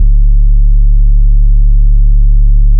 BASS PROFOND.wav